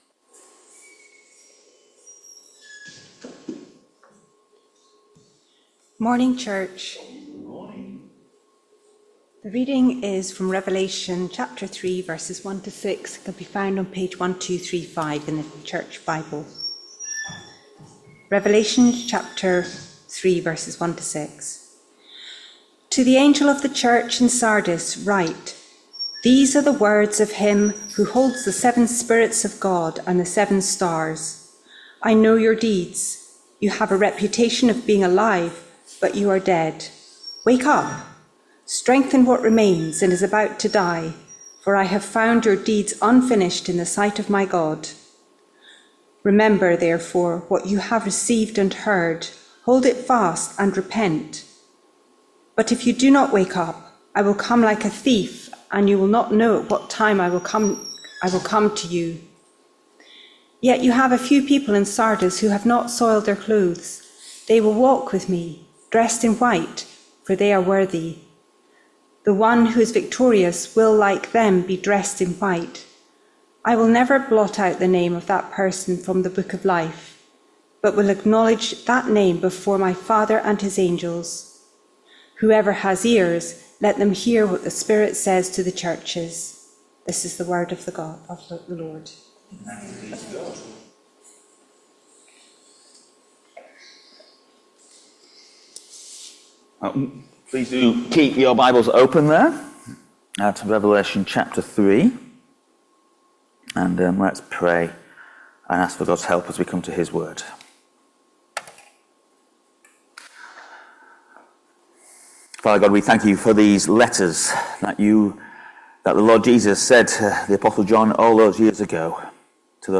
Revelation 3v1-6 Service Type: Sunday Morning Service Topics